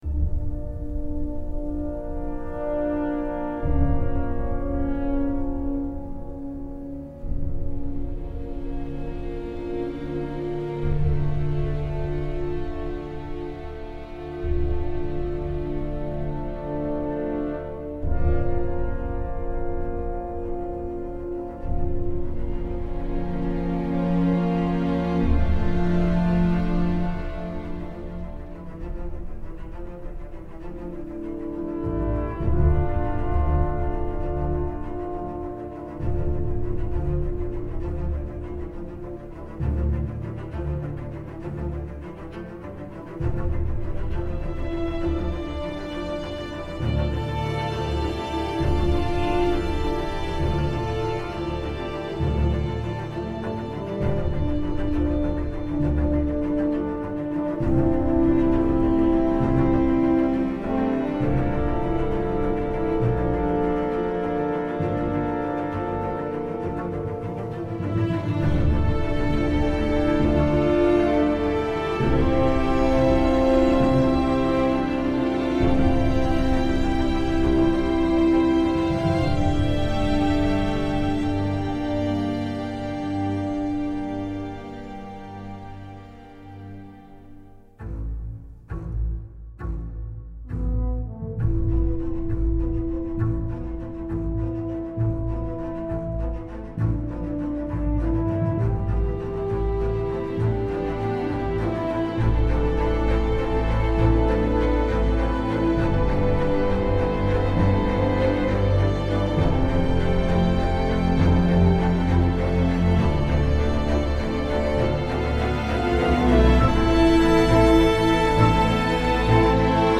partition tendue et profondément mélancolique
Musique jamais bruyante mais toujours forte en émotion.
entre douceur et suspense vertical.